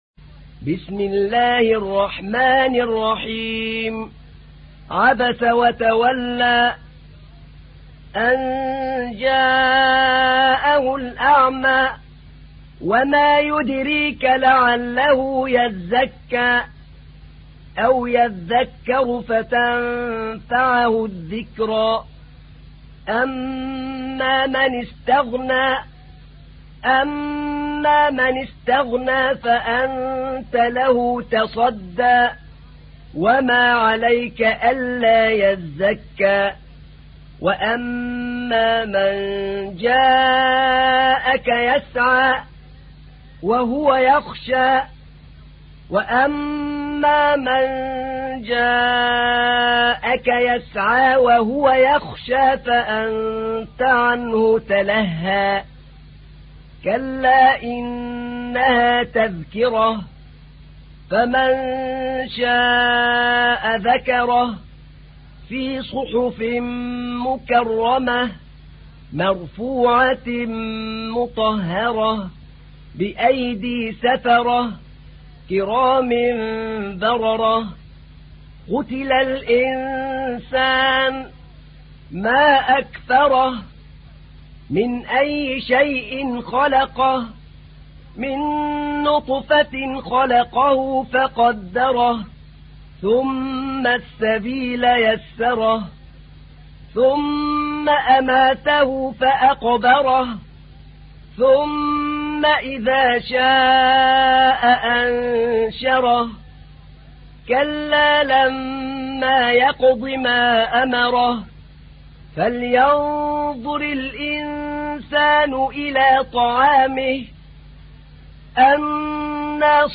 تحميل : 80. سورة عبس / القارئ أحمد نعينع / القرآن الكريم / موقع يا حسين